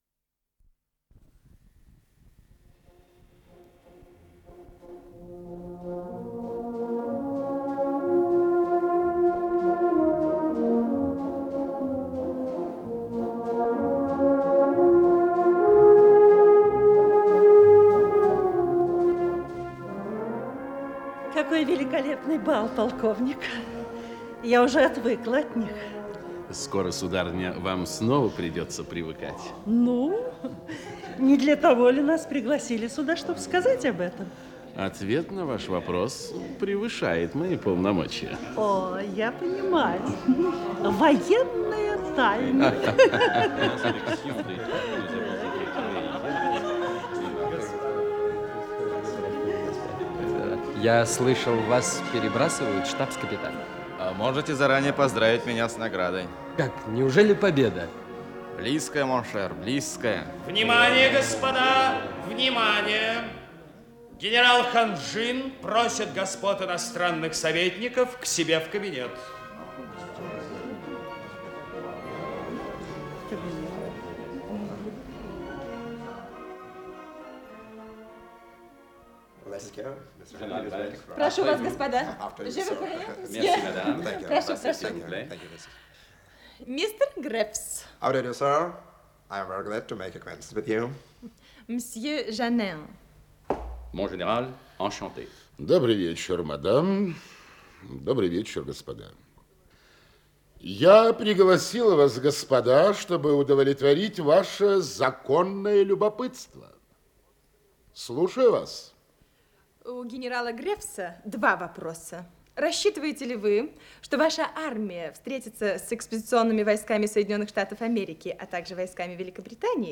Исполнитель: Артисты московских театров
Радиопостановка